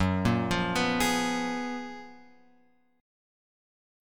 F# Major 11th